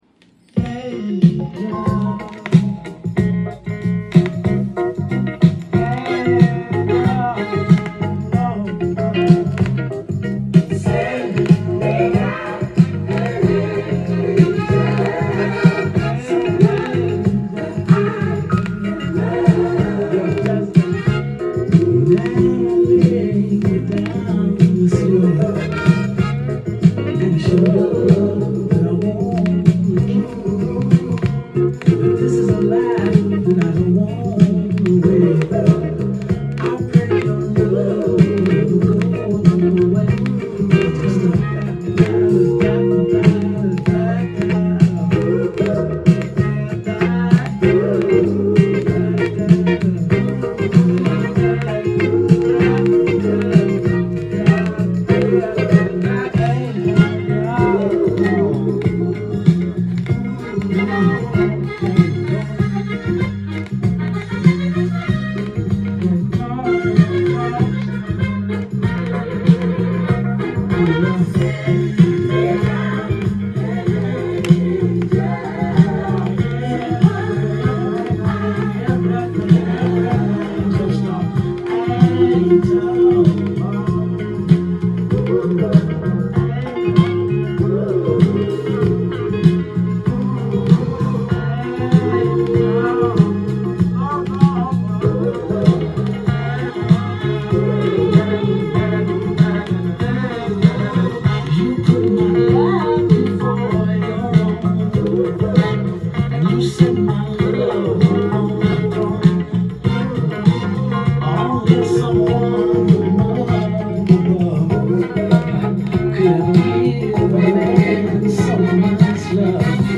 店頭で録音した音源の為、多少の外部音や音質の悪さはございますが、サンプルとしてご視聴ください。
盤｜ VG -　（所々プチプチ音あり）